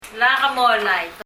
綴りのままに発音記号を起こしてみると、[mŋu:] になるのですが、 発音を聴くと、どうしても[ŋ]は聴き取れません。